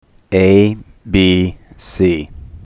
Here is the same statement
amplified:
abcamplified128.mp3